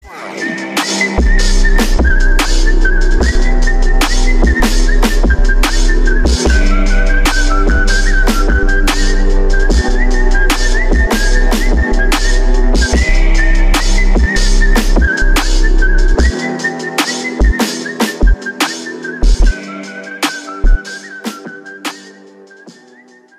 • Качество: 320, Stereo
свист
громкие
Хип-хоп
качающие
instrumental hip-hop